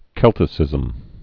(kĕltĭ-sĭzəm, sĕl-)